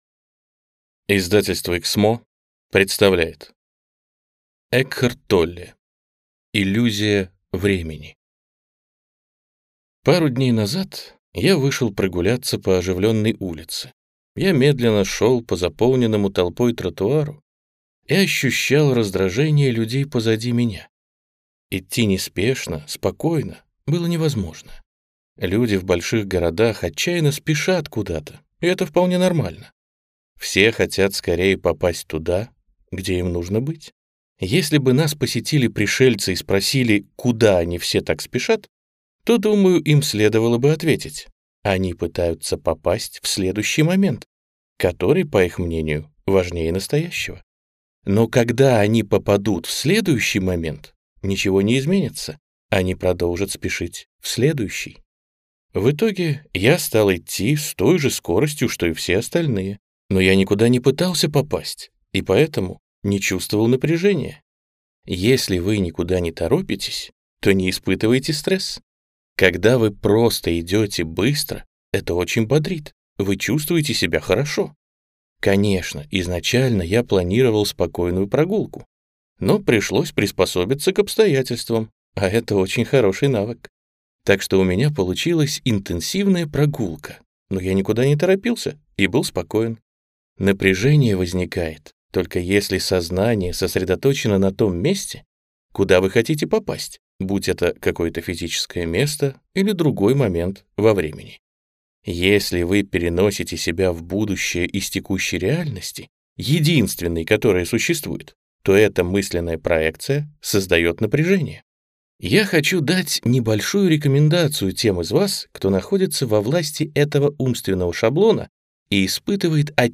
Аудиокнига Иллюзия времени | Библиотека аудиокниг